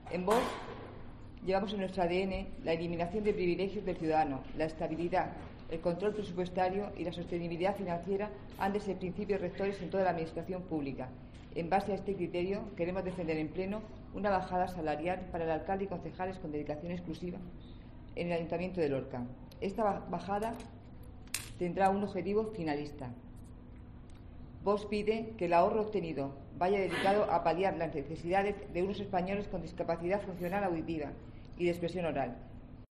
Carmen Menduiña, portavoz de Vox
En una rueda de prensa ha explicado el partido entendió que solo se sometía a votación la asignación económica para los grupos municipales y no los emolumentos de los miembros de la corporación local.